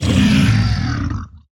assets / minecraft / sounds / mob / zoglin / death2.ogg
death2.ogg